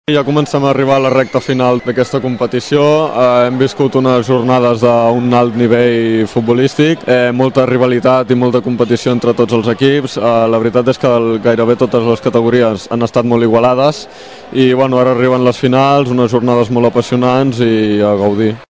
Ahir en declaracions al programa la Banqueta d’estiu